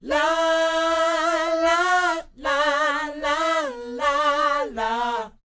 NA-NA A AU-R.wav